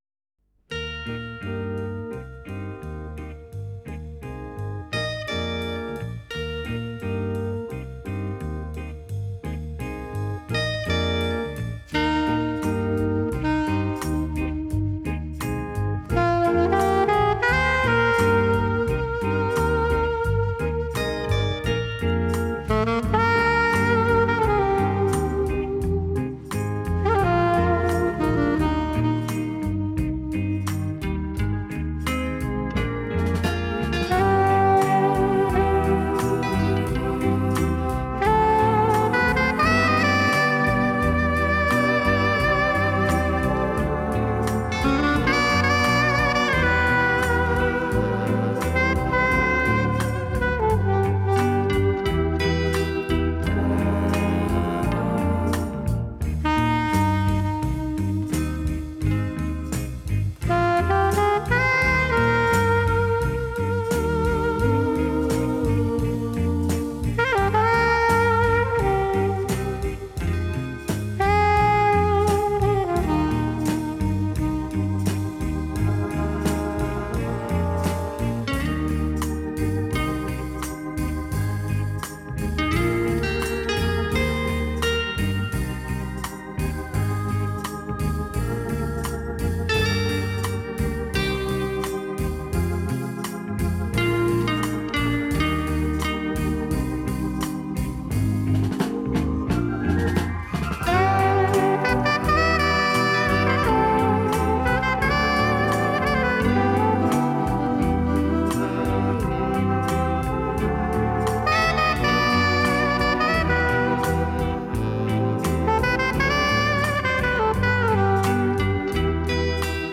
Genre: Instrumental.